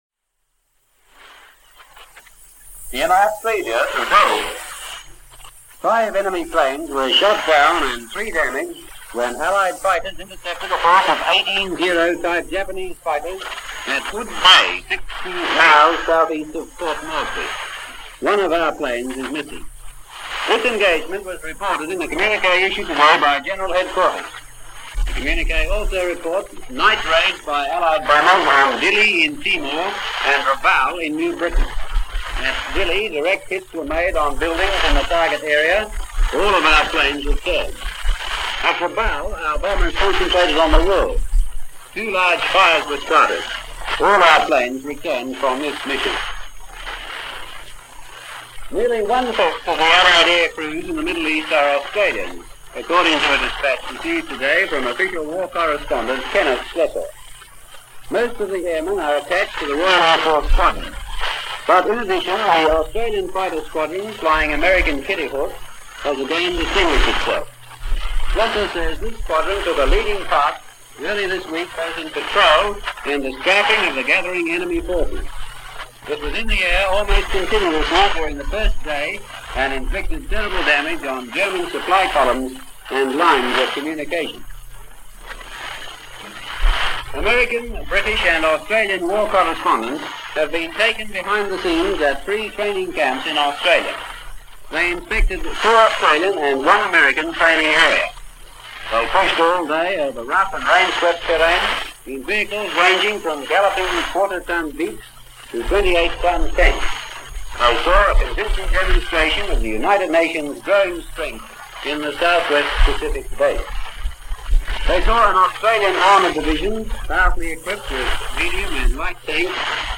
News of the South Pacific by way of Radio Australia and the latest reports on the defense of Port Moresby in this edition of Australia Today picked up via shortwave.